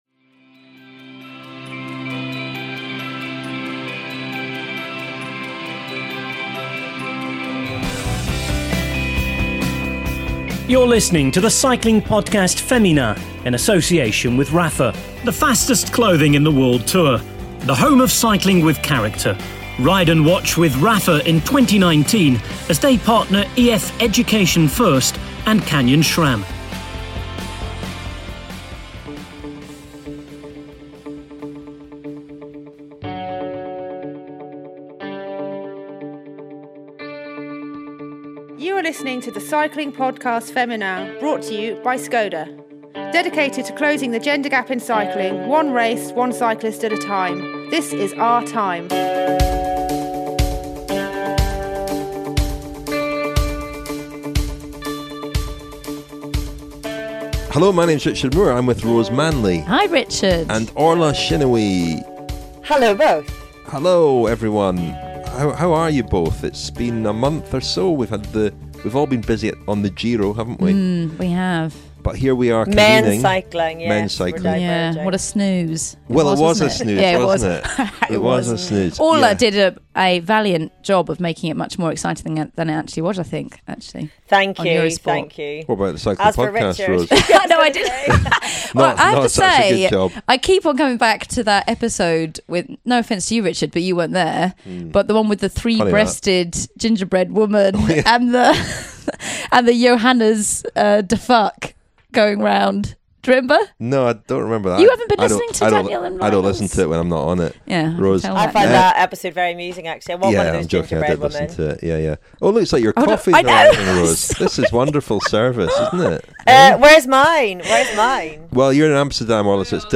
And we hear from Dame Sarah Storey on the new Skoda Cycling Academy.